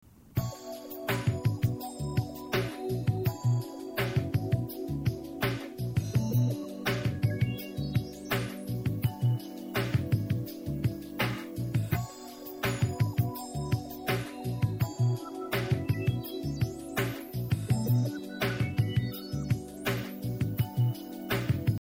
Subject: Name of this instrumental-clip?